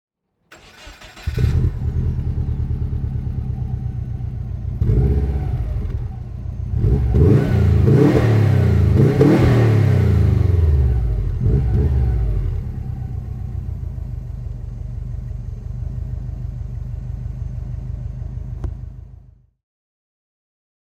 Subaru Impreza GT Turbo (2000) - Starten und Leerlauf
Subaru_Impreza_Turbo_2000.mp3